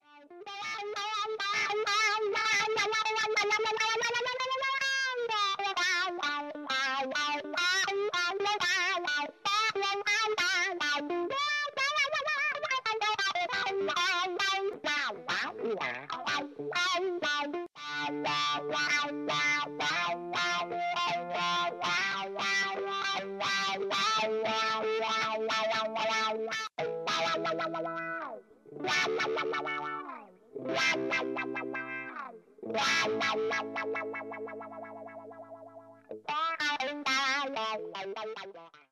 Chase-Tone-Custom-Italian-Crybaby-Replica1.mp3